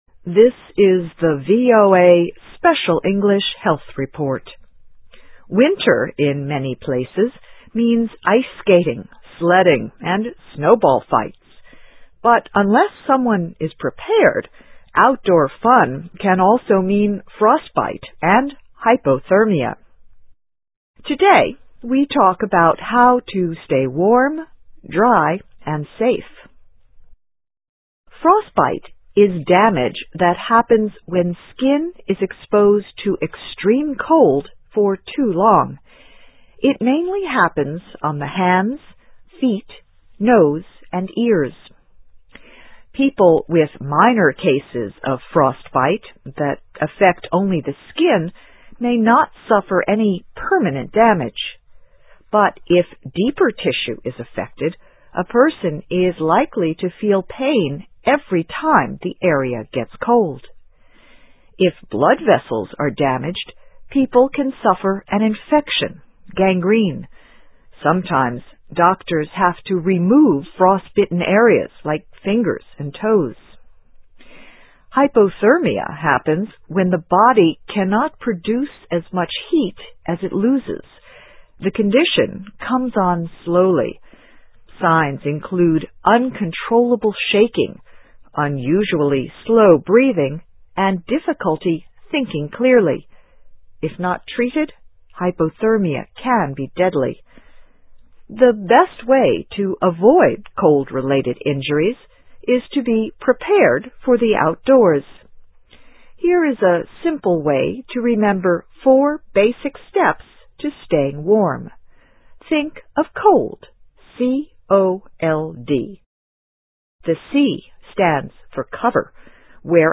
Topic: Four steps to take to avoid frostbite and hypothermia. Transcript of radio broadcast.